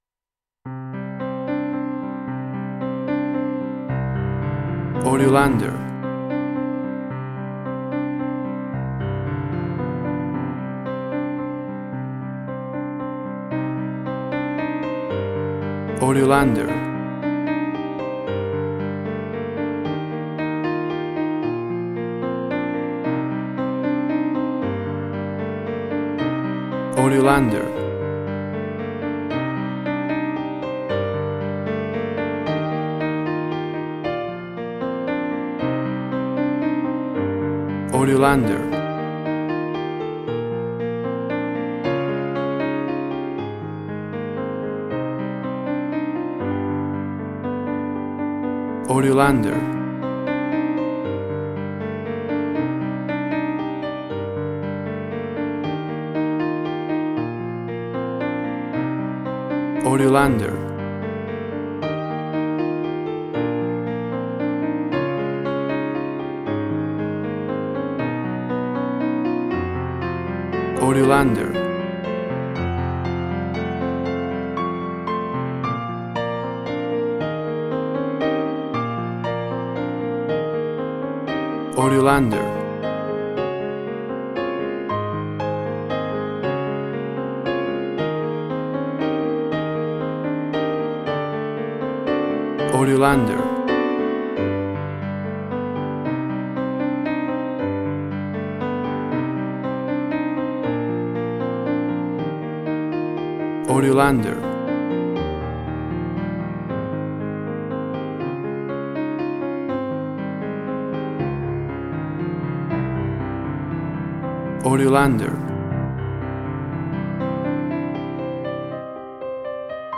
Emotional Piano music.
Tempo (BPM): 116